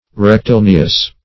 Rectilineous \Rec`ti*lin"e*ous\ (-?s), a.
rectilineous.mp3